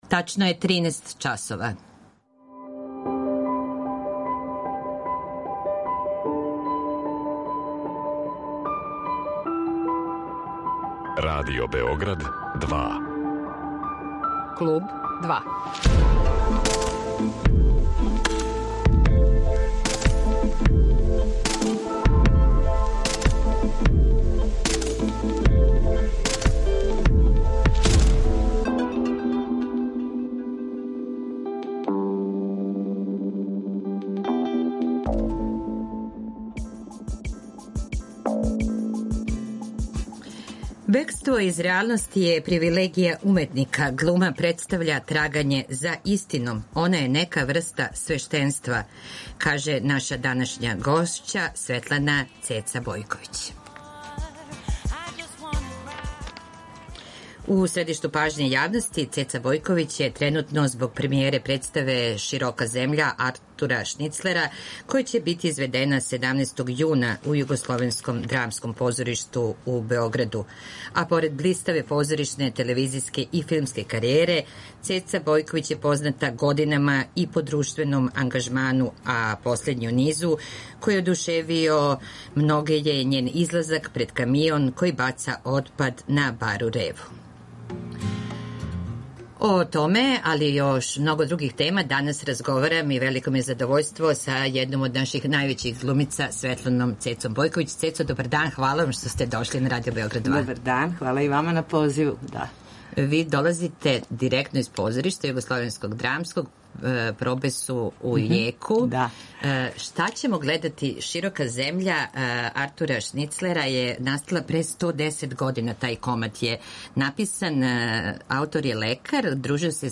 Гошћа емисије Клуб 2 је глумица Светлана Цеца Бојковић.